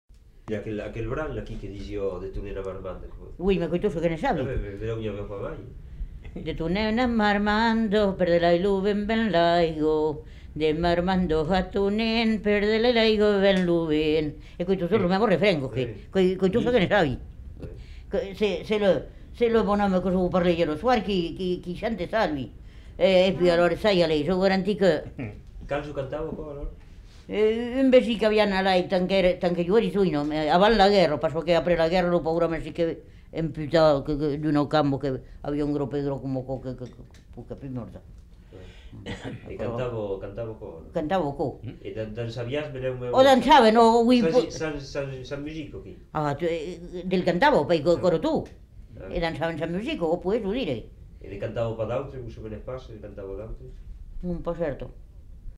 Aire culturelle : Haut-Agenais
Lieu : Roumagne
Genre : chant
Effectif : 1
Type de voix : voix de femme
Production du son : chanté
Danse : rondeau